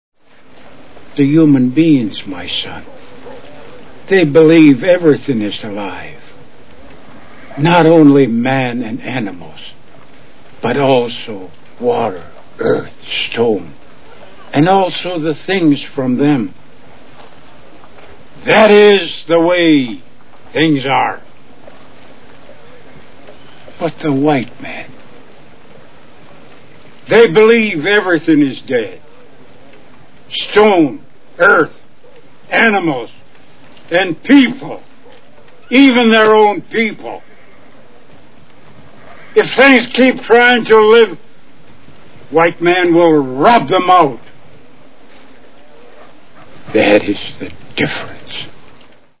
Little Big Man Movie Sound Bites